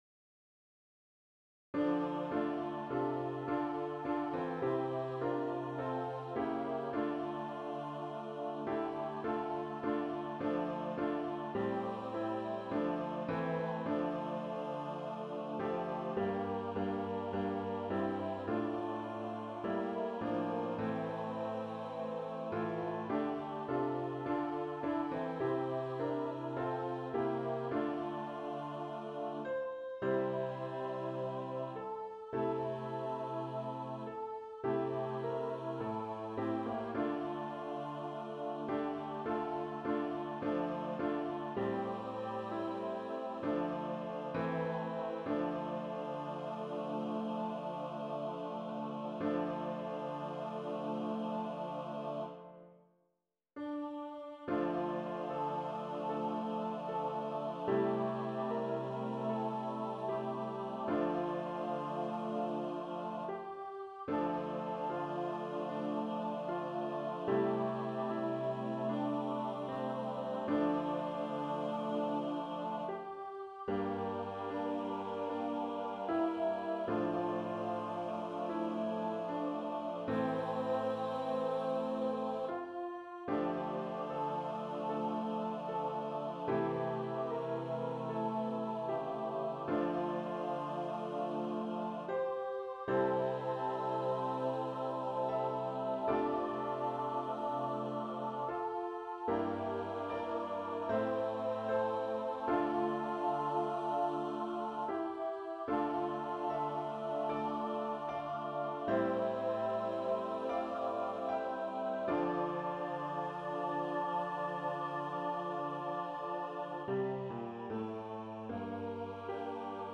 SATB A Capella Choir
Christian, Gospel, Sacred, Christmas.